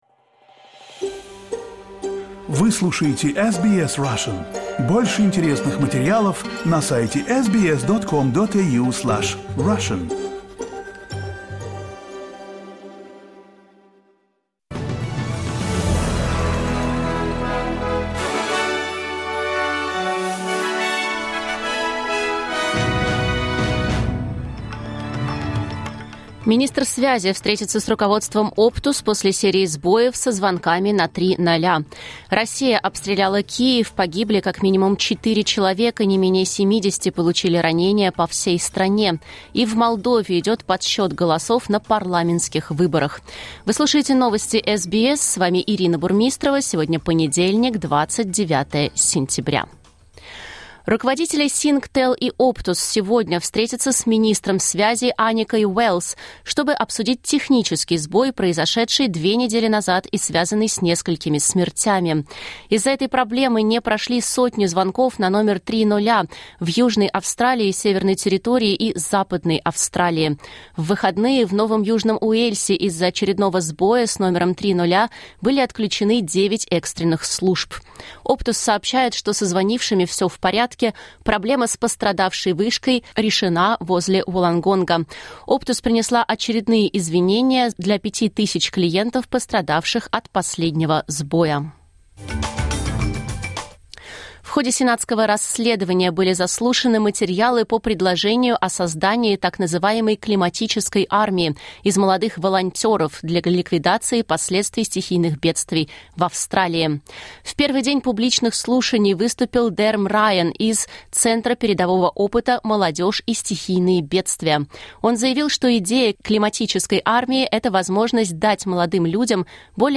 Новости SBS на русском языке — 29.09.2025